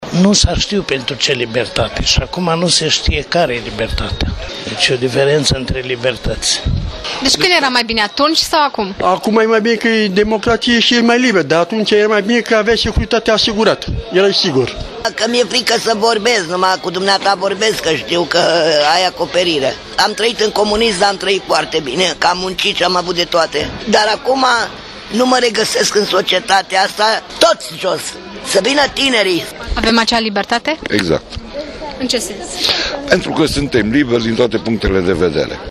Dar cum se simt astăzi, aflăm chiar de la ei.